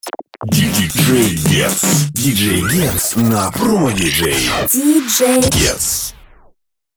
Радио ID Джингл